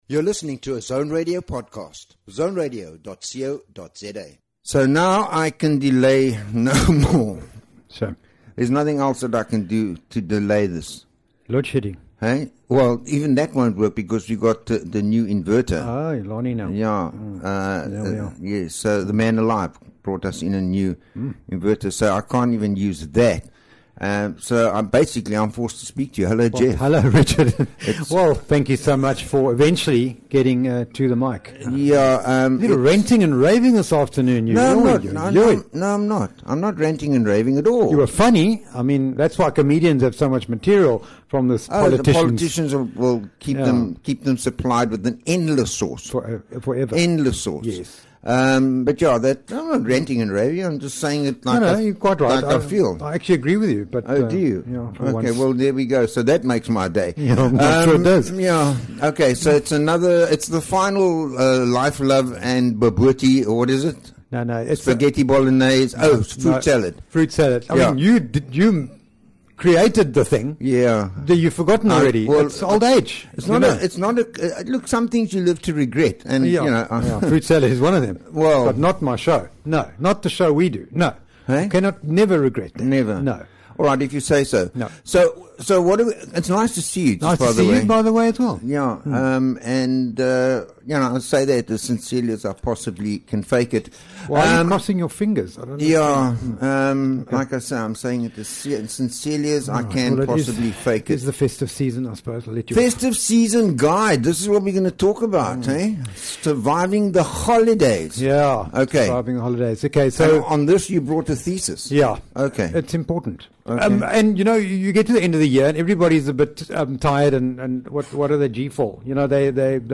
is a bi-weekly radio show exploring the rich tapestry of human experiences. Delve into the sweet and tangy moments of life, savoring conversations on relationships, wellbeing, and the flavors that make up our existence.